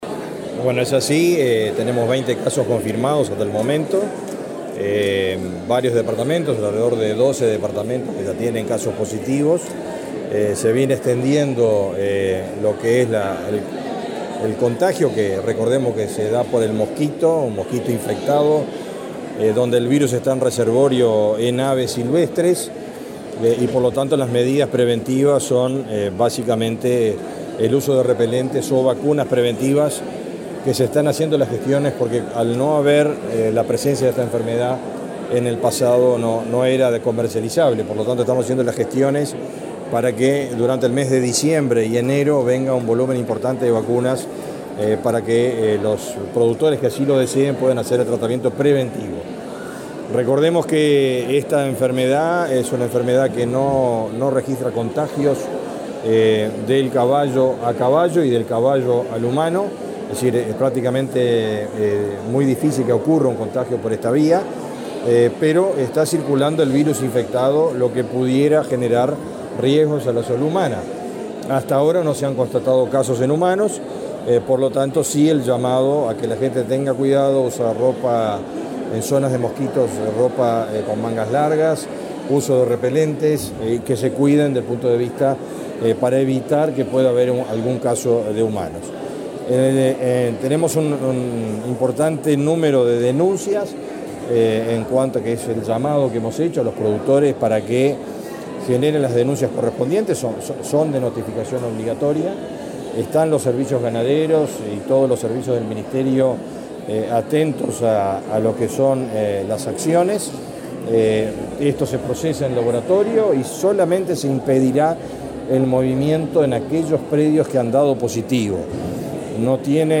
Declaraciones a la prensa de autoridades del Ministerio de Ganadería
Luego Mattos y De Freitas dialogaron con la prensa.